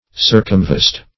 Search Result for " circumvest" : The Collaborative International Dictionary of English v.0.48: Circumvest \Cir`cum*vest"\, v. t. [L. circumvestire; circum + vestire to clothe.]